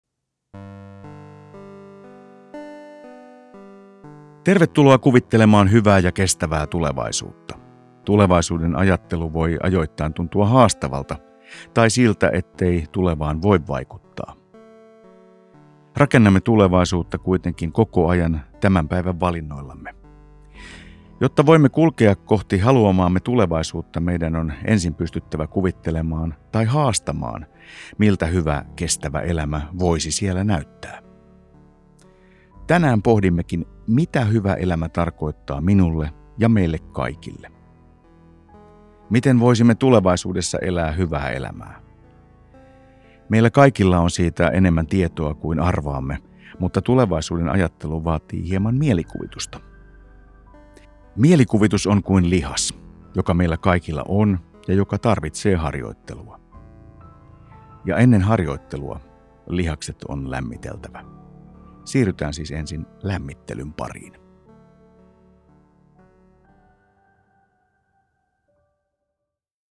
Äänite on helppo tapa kuljettaa harjoitusta ja se sisältää äänitehosteita kuvittelun helpottamiseksi ja sopivan tunnelman luomiseksi.